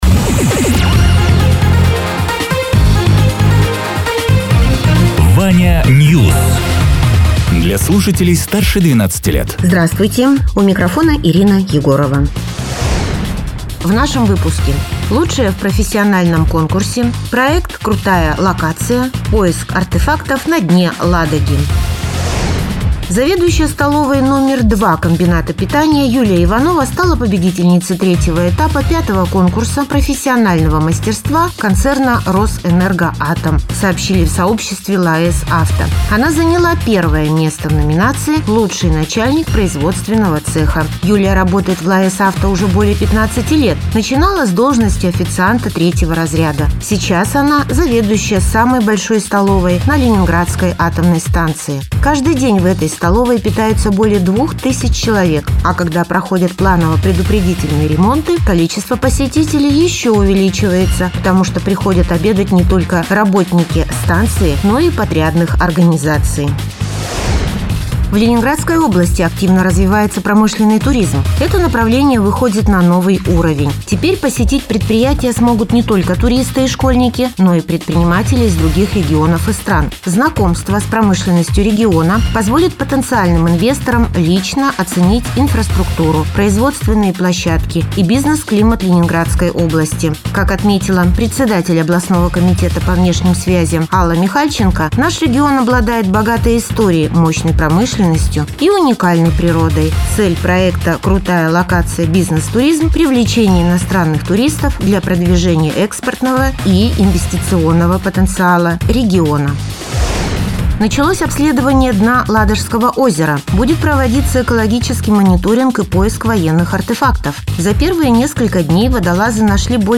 Радио ТЕРА 25.03.2026_08.00_Новости_Соснового_Бора